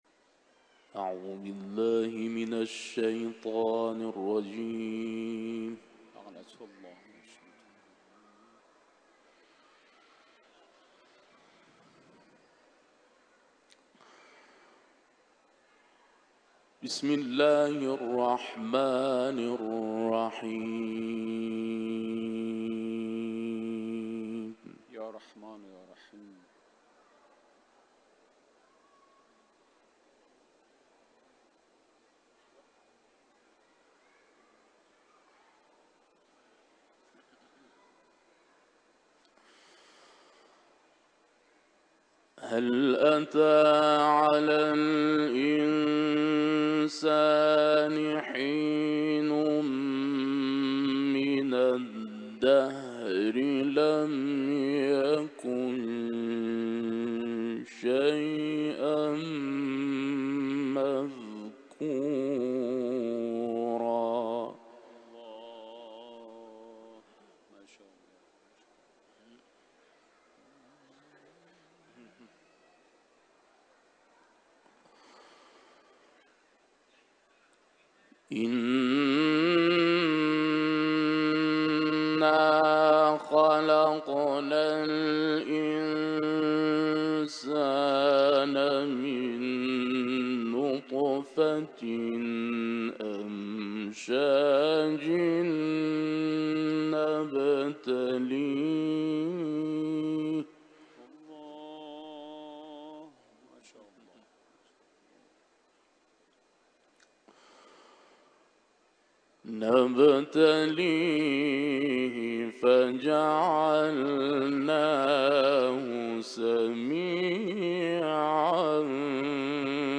IQNA – Uluslararası İranlı kâri İnsân ve Şems suresinden ayetler tilavet etti.